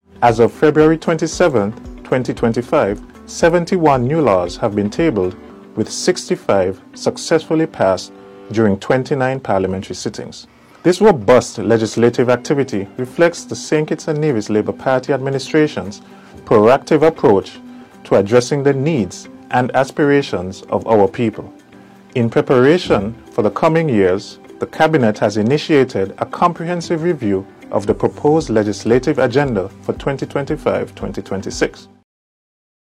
Cabinet Secretary, Marcus Natta.